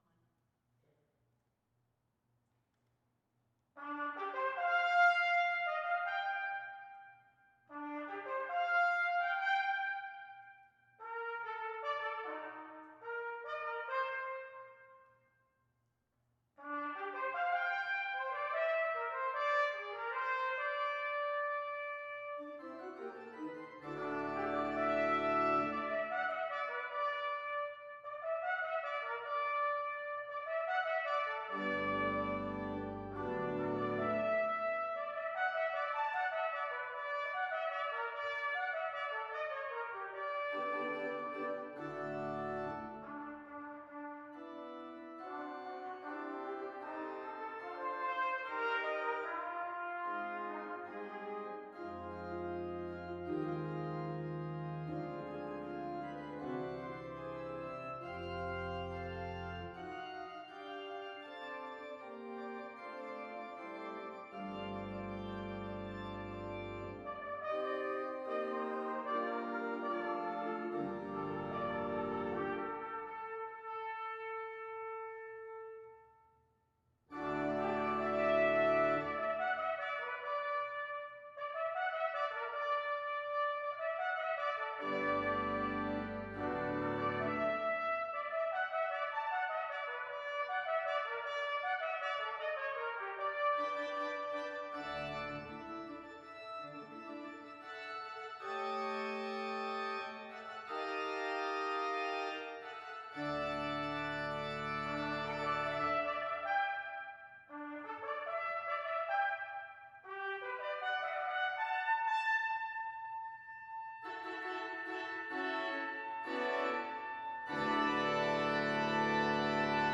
For: Organ & Trumpet
organ